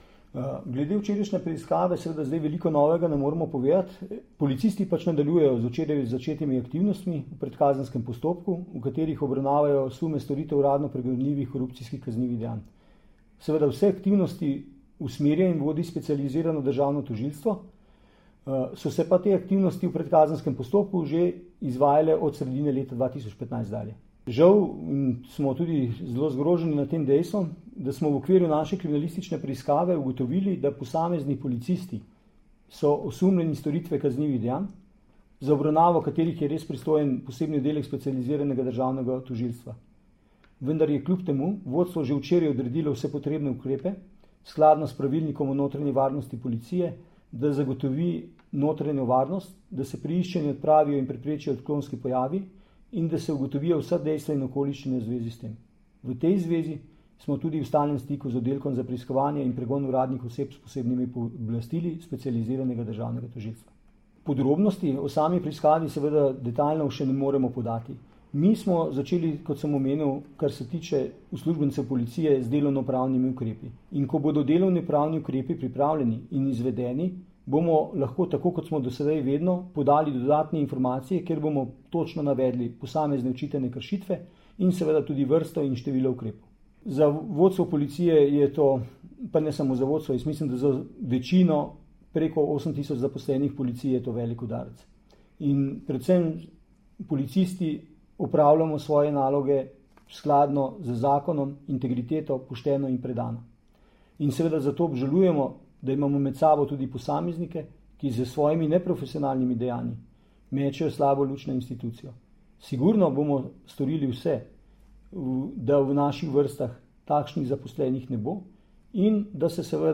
Zvočni posnetek izjave vodje Službe generalnega direktorja policije Tomaža Pečjaka (mp3)